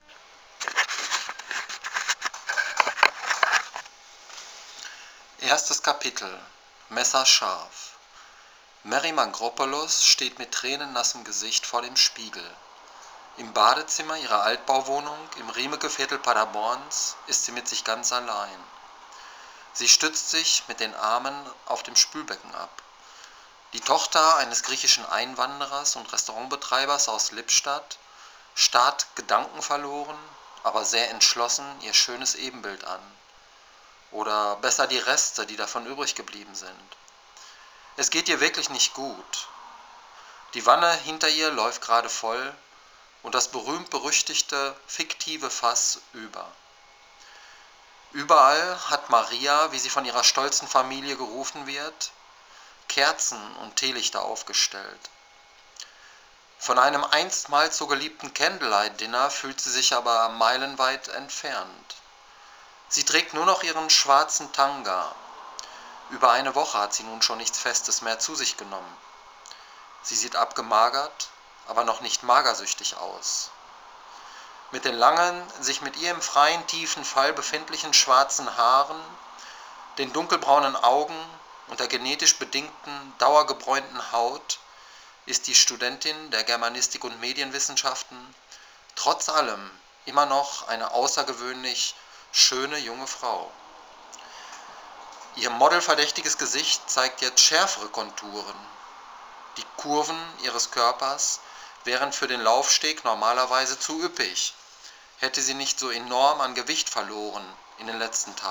Ich dachte, liest Deinen treuen Homepagegästen mal was vor.